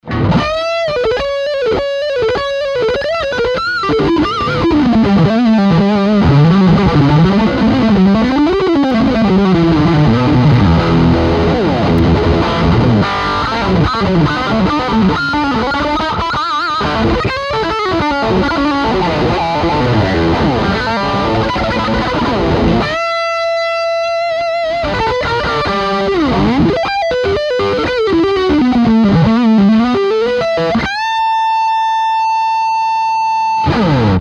Canal Distorsion - HEAVY - Toma 3: Aqui utilizamos una PRS con dos microfonos dobles, posicion de mics 1 y 3 ( Puente y mango)
GTS90 Solo.mp3